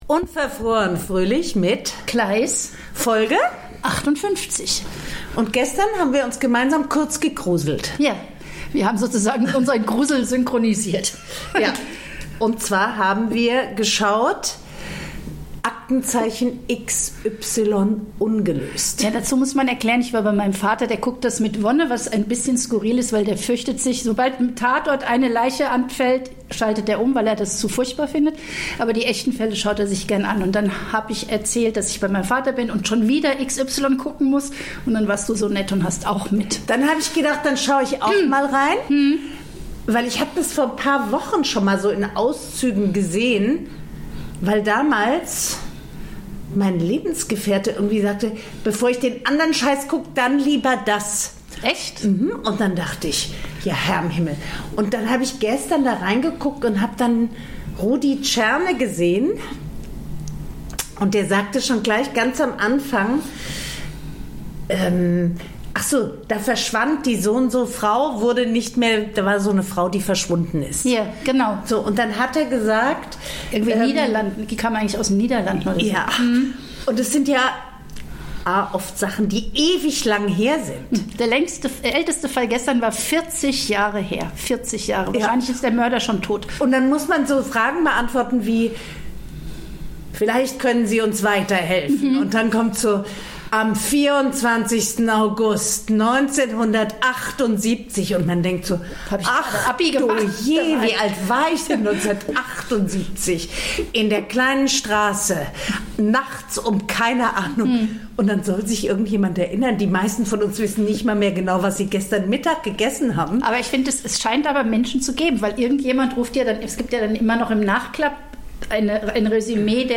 reden die beiden Podcasterinnen über Perlen der Fernsehunterhaltung, das Gepampere von Kindern, Angst- und Jungmacher.